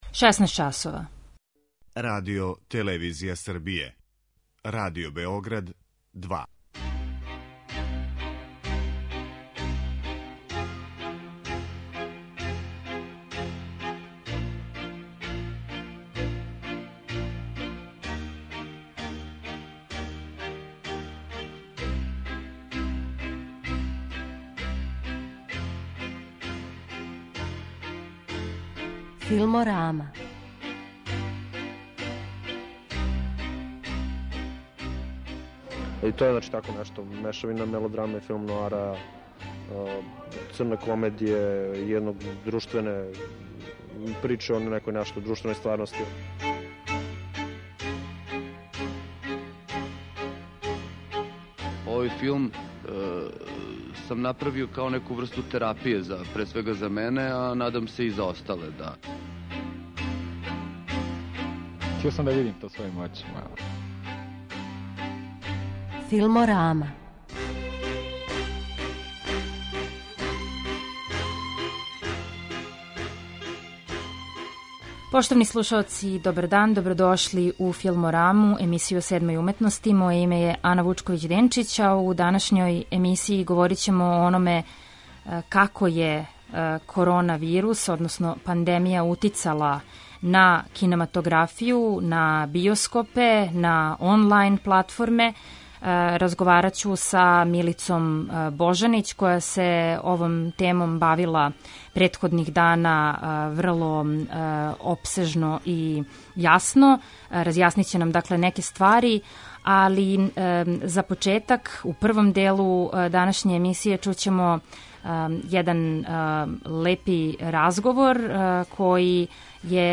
У данашњој емисији чућете разговор са Улрике Отингер овогодишњом добитницом награде Berlinale Camera, коју Међународни фестивал у Берлину додељује људима и институцијама који су посебно допринели развоју кинематографије и са којима је фестивал посебно повезан.
Разговор који ћете чути снимљен је пре десет година на 60. Берлиналу и ово је прво његово емитовање.